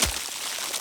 Ice Freeze 2.wav